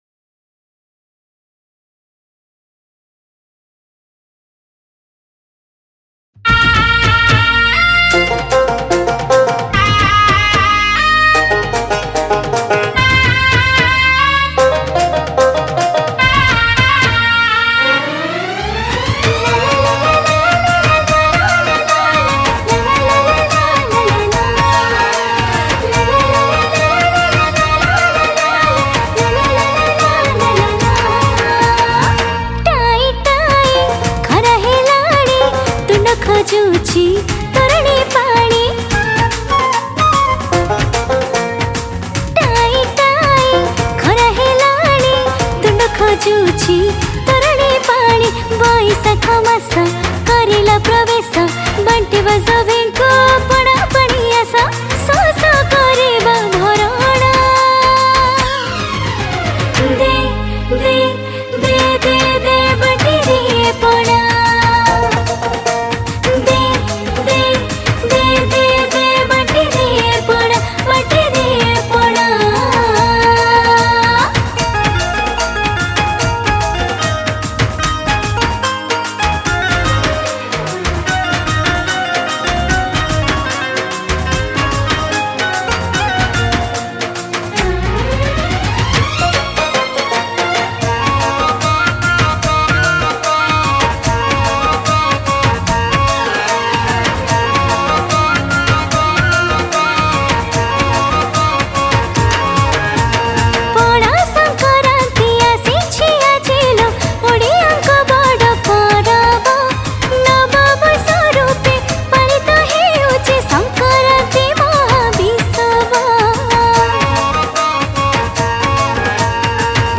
Pana Sankarati Special Bhajan